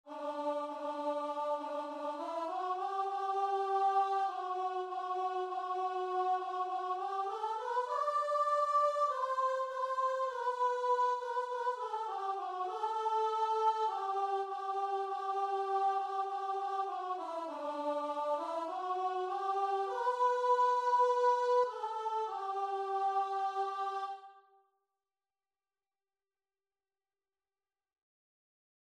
Christian
4/4 (View more 4/4 Music)
Classical (View more Classical Guitar and Vocal Music)